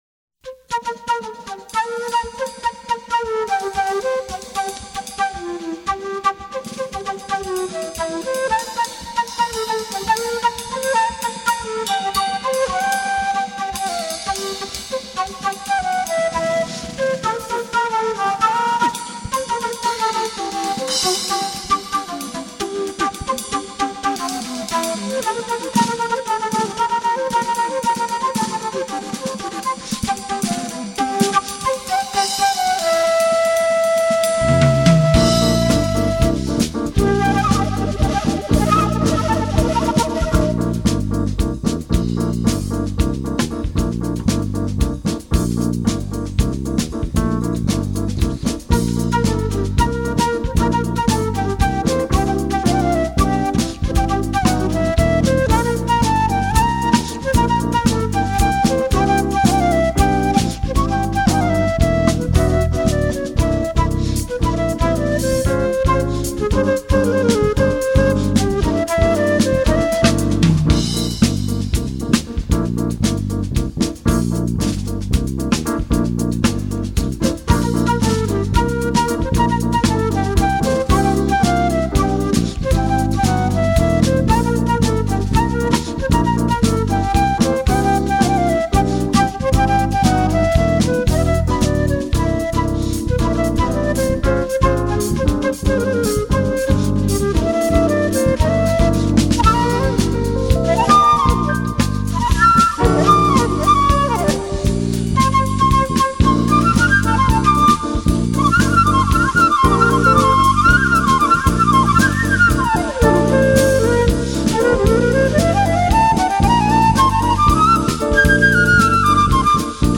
1416   04:59:00   Faixa:     Jazz
Piano Elétrico
Bateria